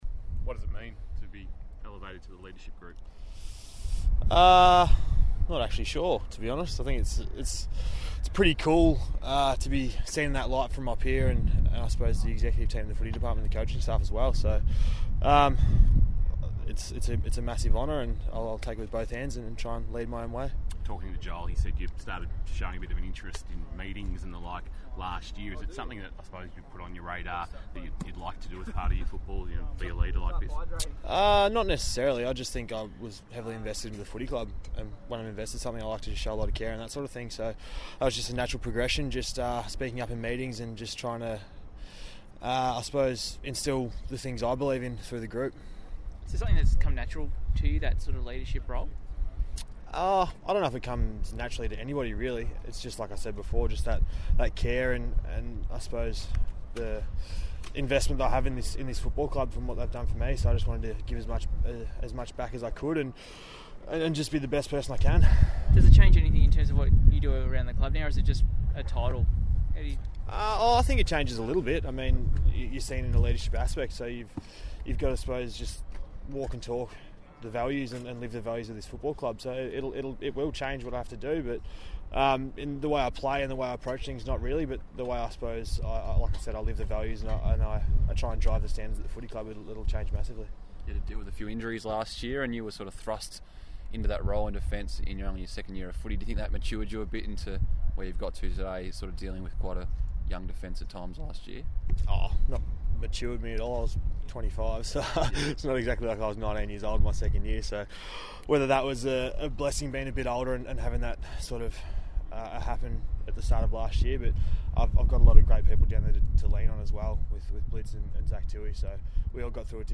Geelong defender Tom Stewart speaks after being elevated to the Cats' leadership group.